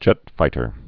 (jĕtfītər)